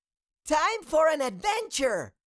Cartoon Little Child, Voice, Time For An Adventure Sound Effect Download | Gfx Sounds
Cartoon-little-child-voice-time-for-an-adventure.mp3